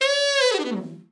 ALT FALL   6.wav